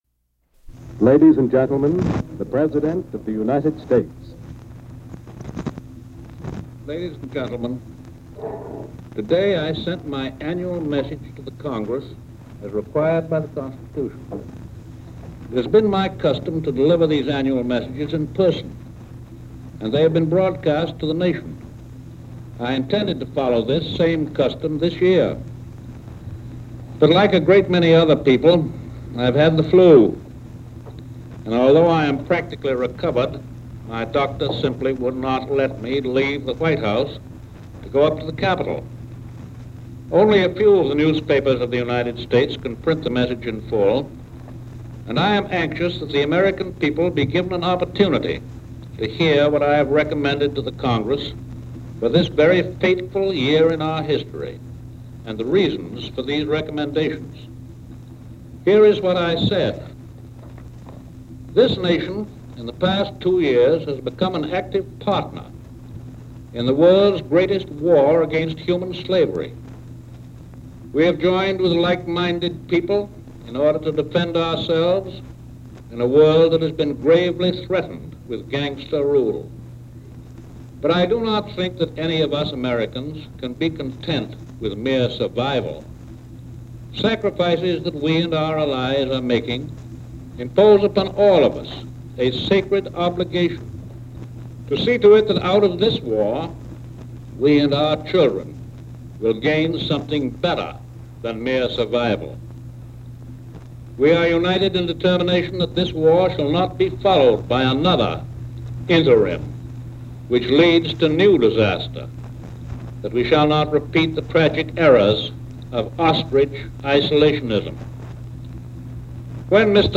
Presidential Speeches | Franklin D. Roosevelt